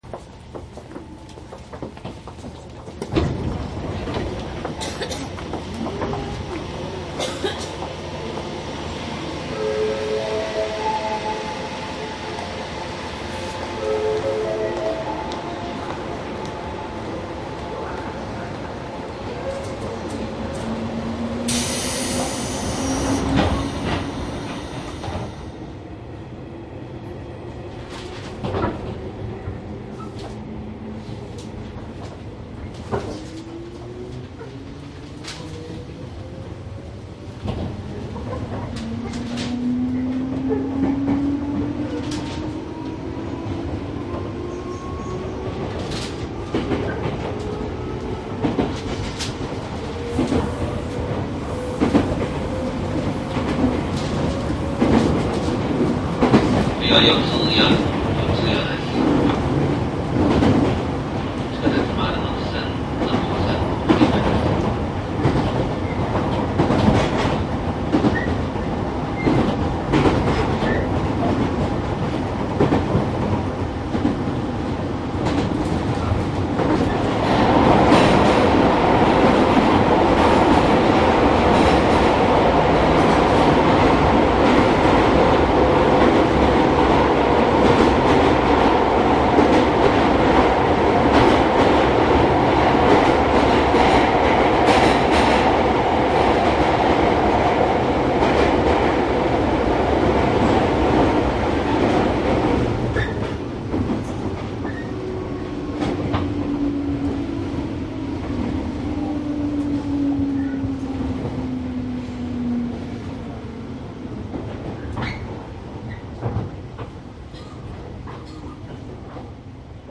総武緩行線 205系走行音 ＣＤ♪
■【各駅停車】三鷹→津田沼（津田沼ゆき）モハ205-277
マスター音源はデジタル44.1kHz16ビット（マイクＥＣＭ959）で、これを編集ソフトでＣＤに焼いたものです。
車内は比較的空いています。